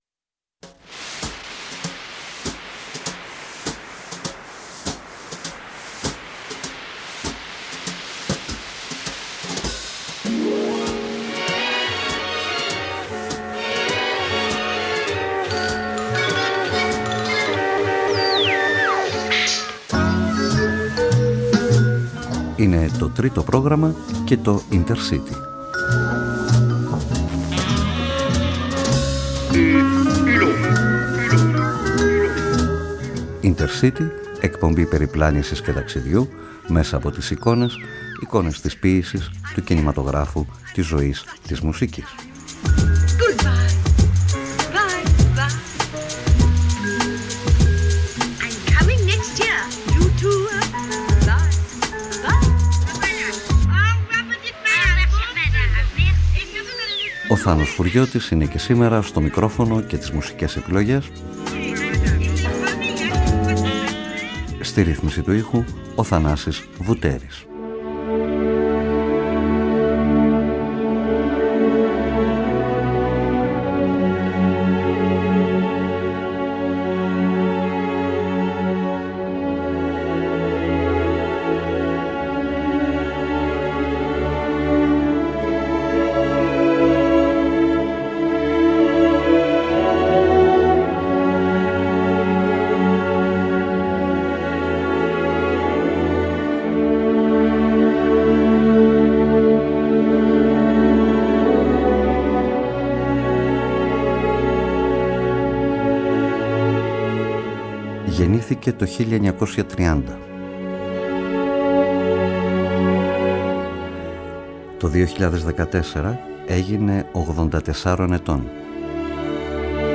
To ραδιοφωνικό βιβλίο της ζωής και του έργου του Jean-Luc Gοdard (3 Δεκεμβρίου 1930 -13 Σεπτεμβρίου 2022).
GOD ART JLG (1930 -2022) To ραδιοφωνικό βιβλίο της ζωής και του έργου του Jean-Luc Gοdard (3 Δεκεμβρίου 1930 -13 Σεπτεμβρίου 2022). Με την αφήγηση και το ρυθμό να ακολουθούν αναγκαστικά την δική του, μη ευθύγραμμη προσέγγιση των πραγμάτων, Αυθεντικές μουσικές και αποσπάσματα από τις ταινίες του, μαρτυρίες, κείμενα ηχητικές φωτογραφίες, σε μια διαδρομή μνήμης και συναισθημάτων.